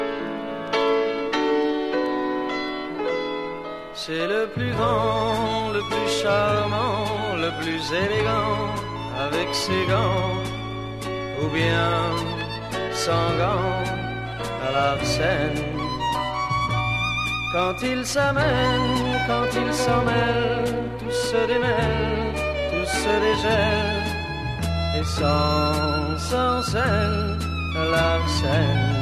Un extrait de la première version du générique